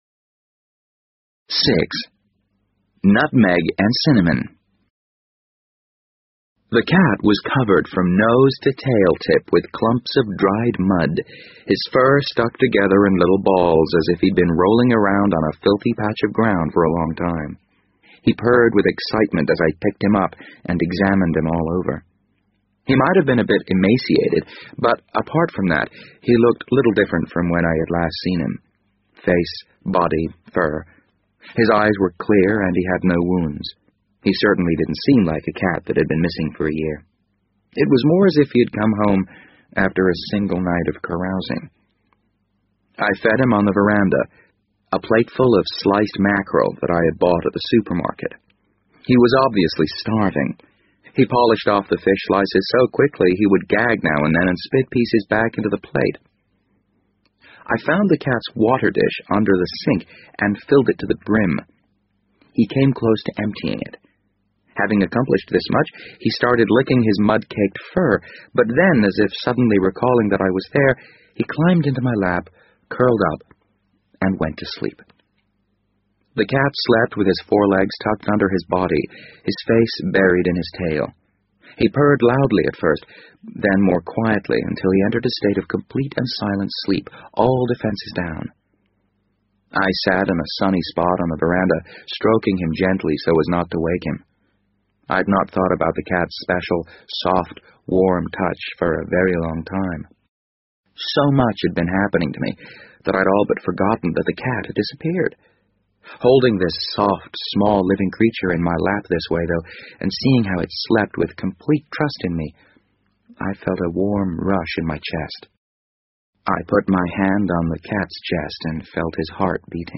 BBC英文广播剧在线听 The Wind Up Bird 010 - 3 听力文件下载—在线英语听力室